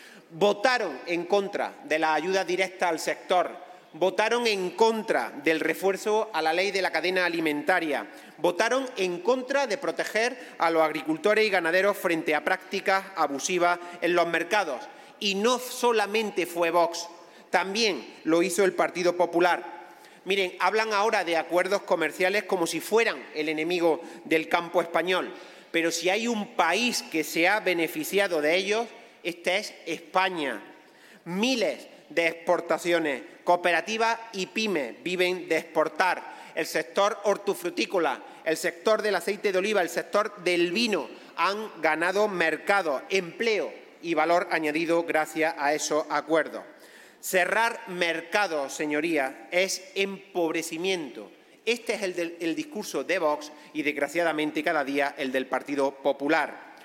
En el debate de una iniciativa de Vox contra el Pacto Verde Europeo, el senador socialista recalcó que “si hay un país que se ha beneficiado de los acuerdos comerciales, ése es España”, con un sector del aceite de oliva que “ha ganado mercados, empleo y valor añadido gracias a esos acuerdos”.